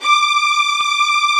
Index of /90_sSampleCDs/Roland - String Master Series/CMB_Combos 2/CMB_Full Section
STR STRING0E.wav